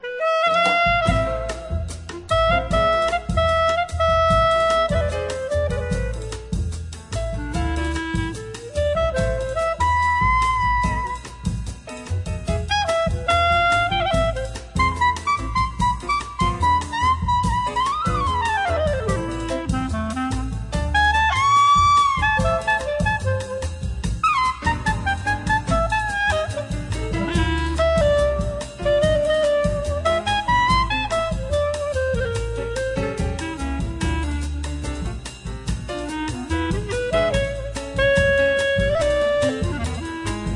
The Best In British Jazz
Recorded Curtis Schwartz Studios January 2003